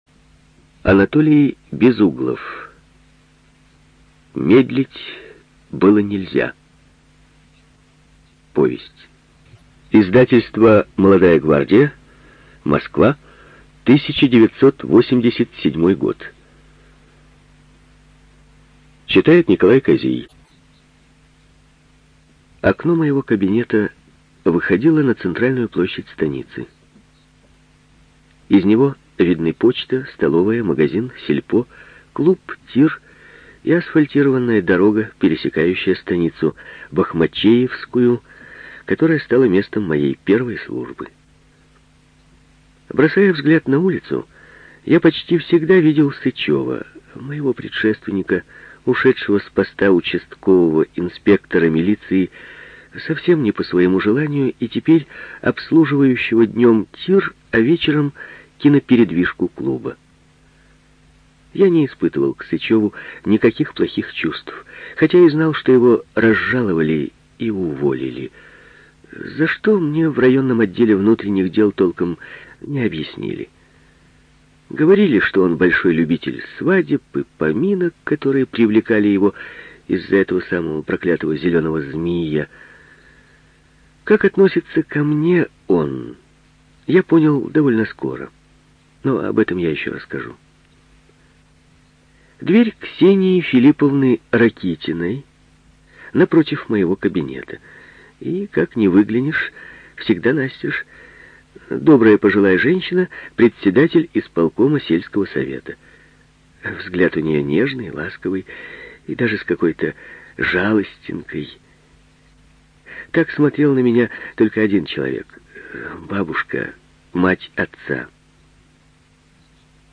ЖанрСоветская проза
Студия звукозаписиРеспубликанский дом звукозаписи и печати УТОС